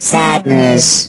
rick_die_01.ogg